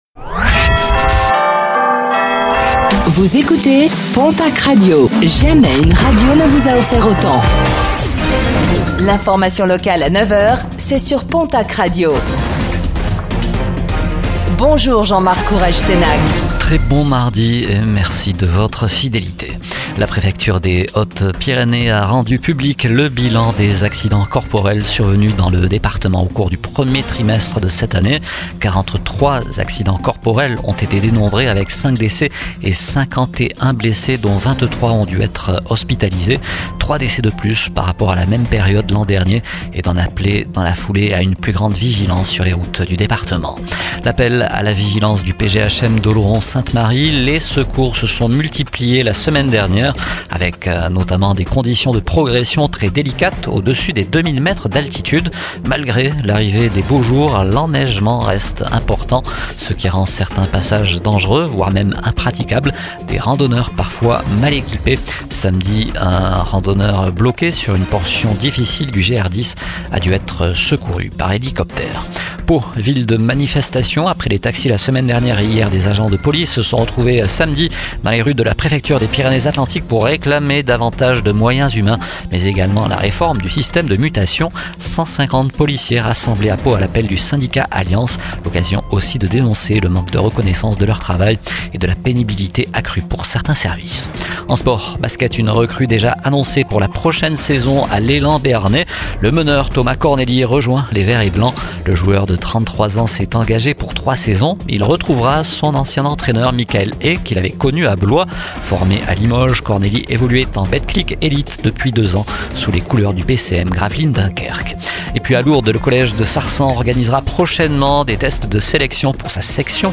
Réécoutez le flash d'information locale de ce mardi 27 mai 2025